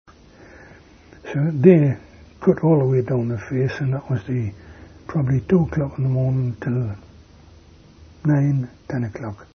interview
Narrative History